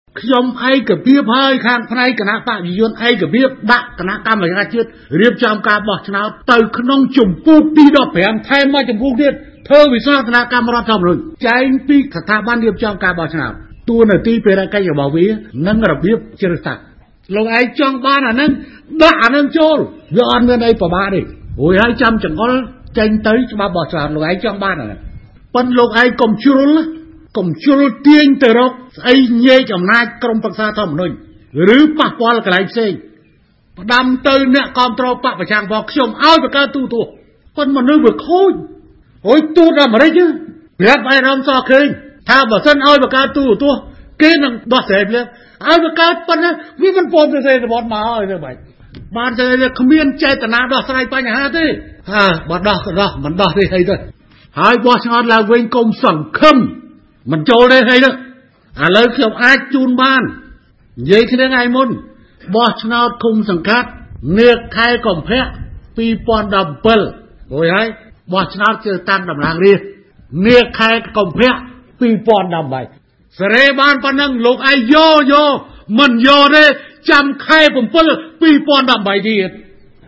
ប្រសាសន៍លោក នាយករដ្ឋមន្ត្រី ហ៊ុន សែន
Sound_Hun_Sen.mp3